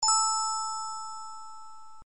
bell nș 03
bell03.mp3